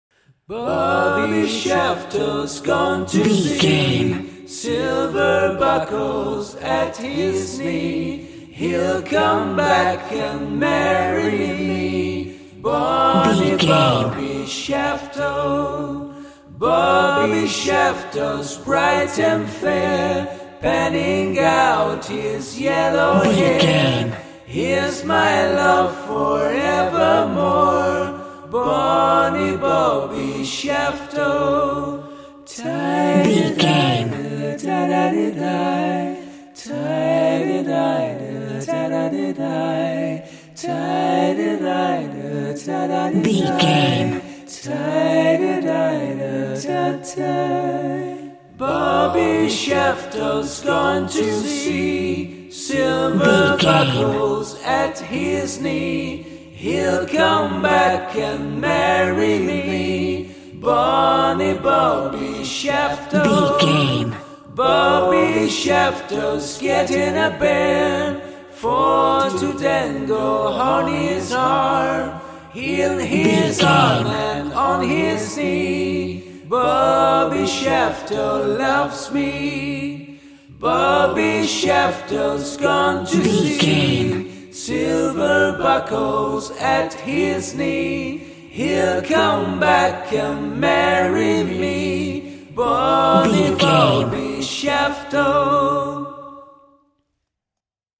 Ionian/Major
D♭
nursery rhymes
childlike
happy